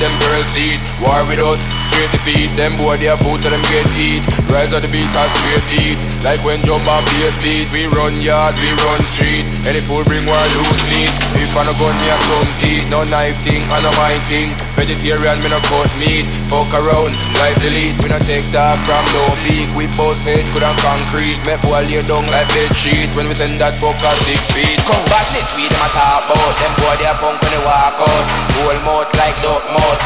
TOP >Vinyl >Grime/Dub-Step/HipHop/Juke
TOP > Vocal Track